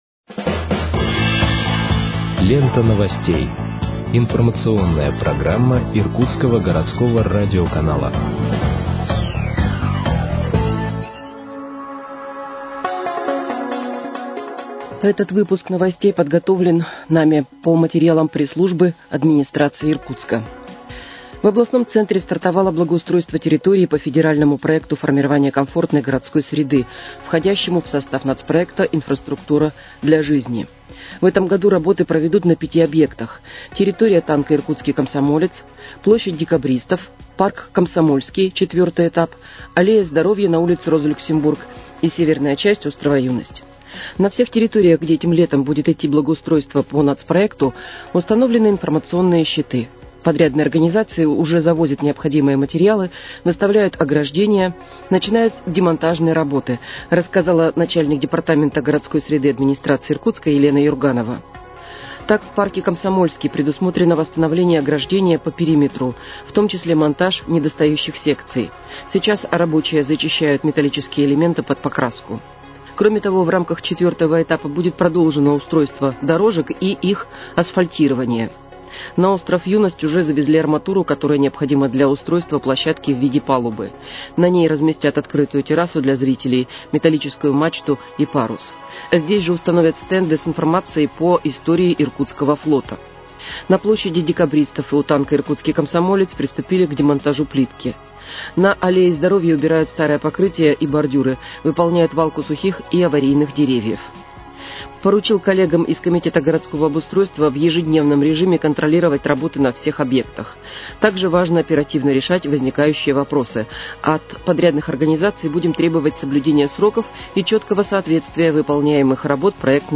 Выпуск новостей в подкастах газеты «Иркутск» от 20.05.2025 № 2